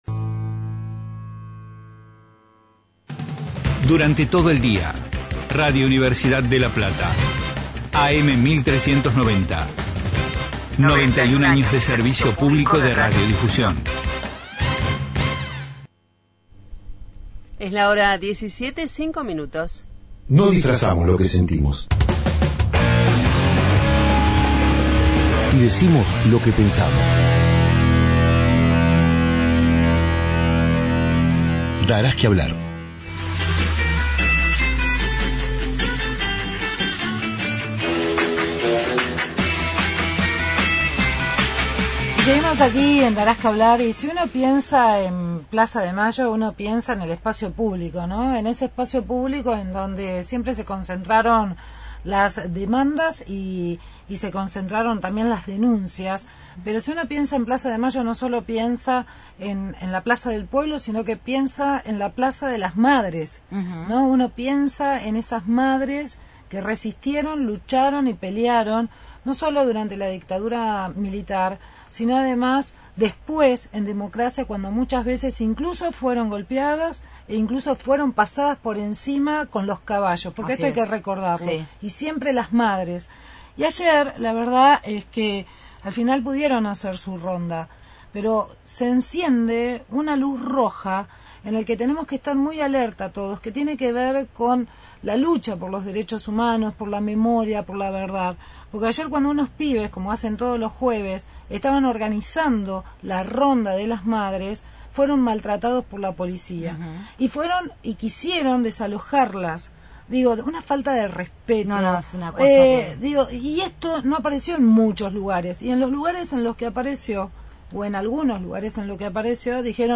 Entrevista a Hebe de Bonafini por que intentaron impedir que la realización de la tradicional ronda de los jueves en plaza de Mayo y sobre la visita de Obama en Marzo.